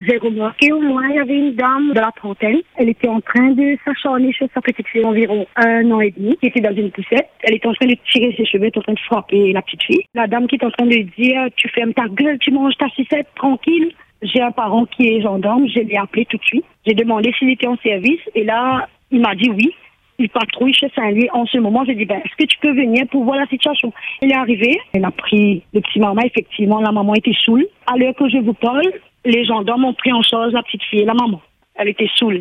« J’étais sous le choc » : une auditrice raconte une scène de violence sur un bébé